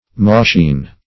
Search Result for " moschine" : The Collaborative International Dictionary of English v.0.48: Moschine \Mos"chine\, a. Of or pertaining to Moschus , a genus including the musk deer.